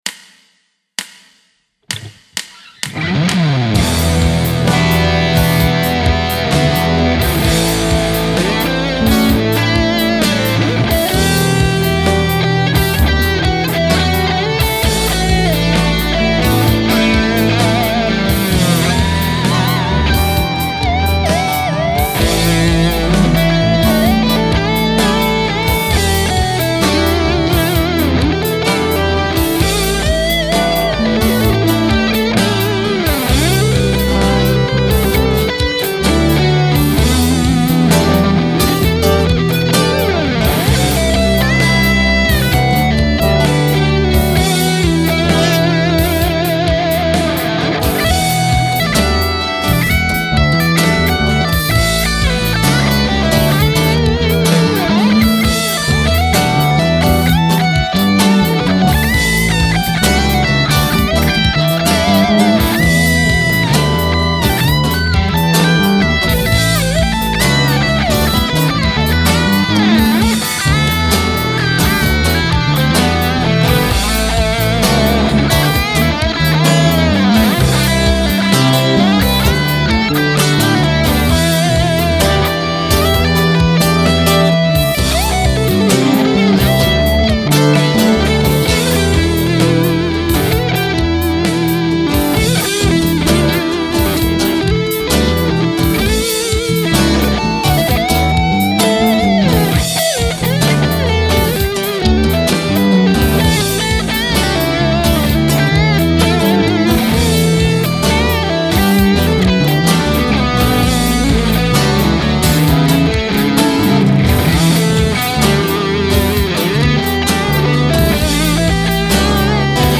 Blues.. ja stimmt.
Das Echo in Jam 32 ist tatsächlich out of time und zu weit vorn gemischt.
Ich hatte das Cubase-Projekttempo auf 64 bpm gestellt, aber es sind 65 bpm.
Die alte 64bpm-Tonnenversion ist hier... wenn es jemanden interessieren sollte